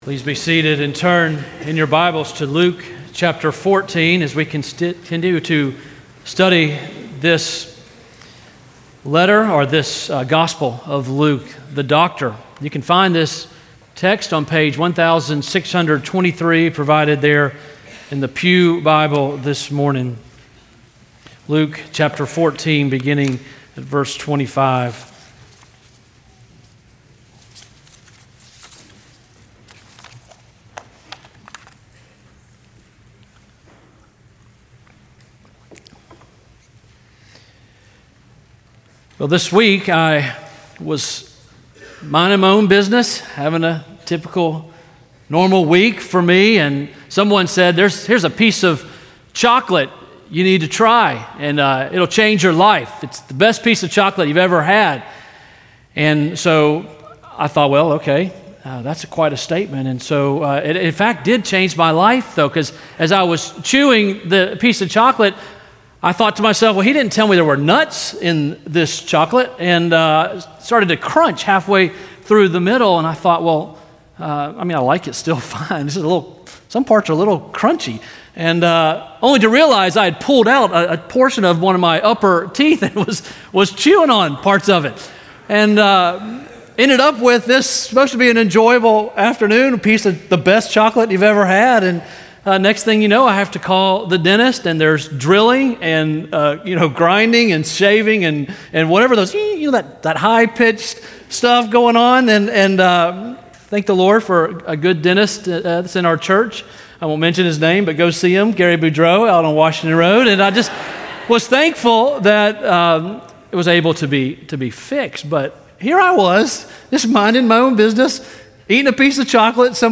From Series: "Sunday Sermons"